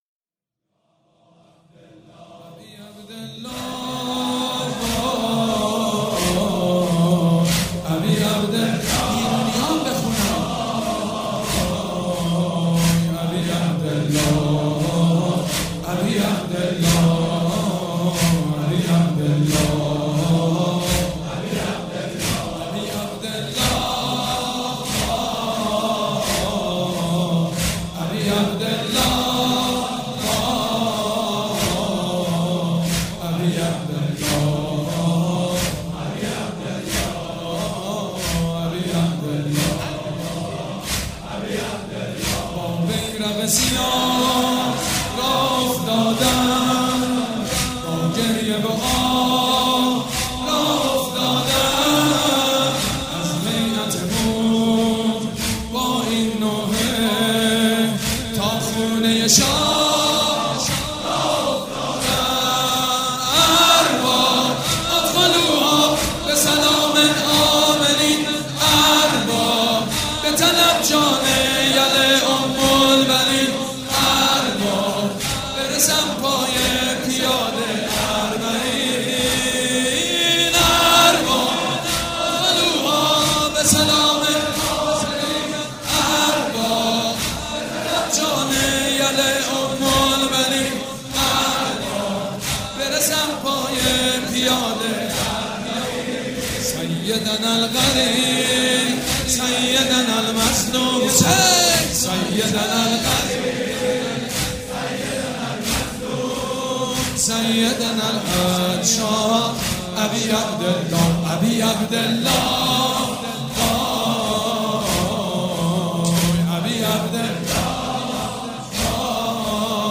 عزاداری محرم
مداحی